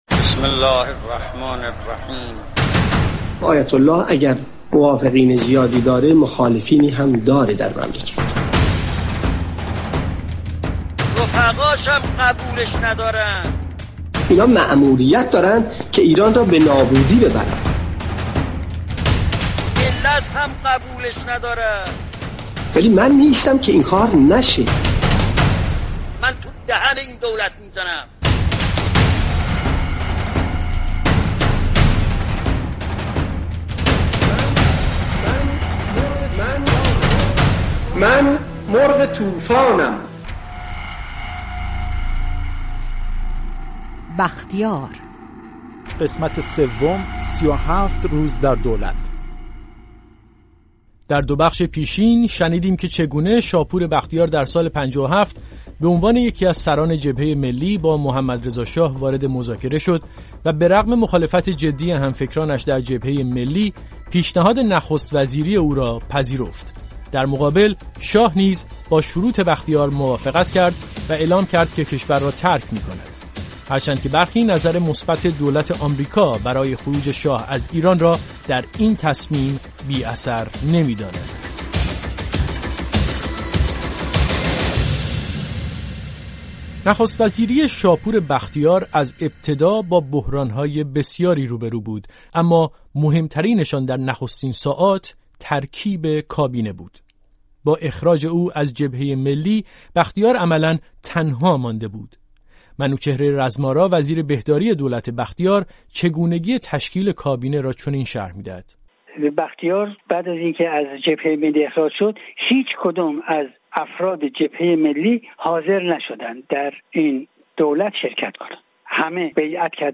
مستند رادیویی